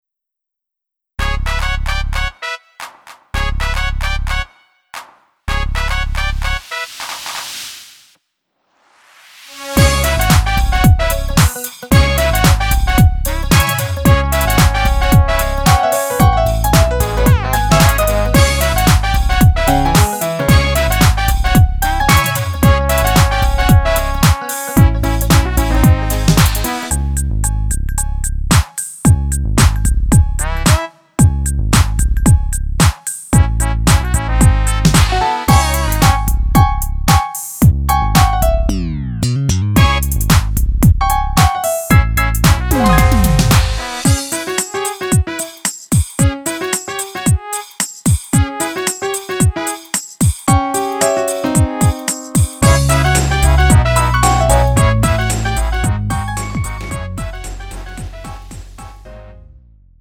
음정 원키